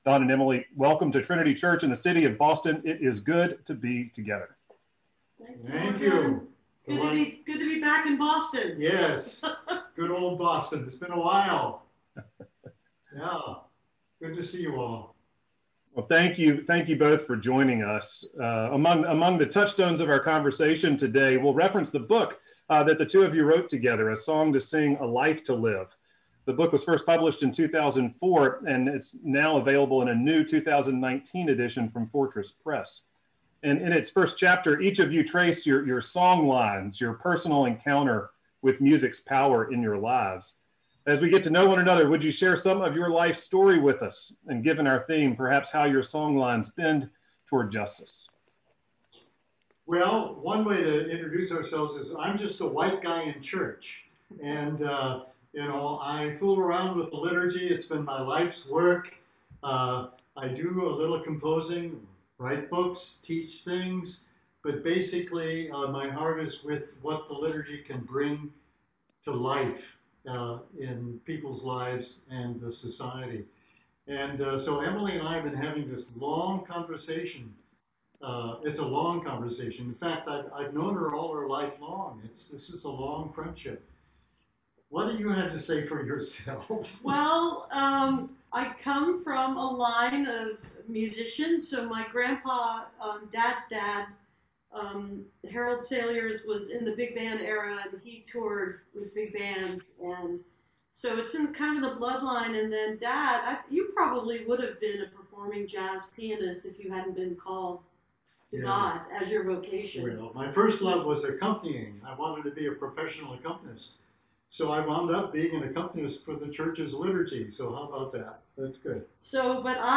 (captured from the zoom livestream)
02. conversation (7:54)